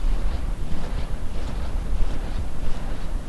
ceiling_fan_blades.L.wav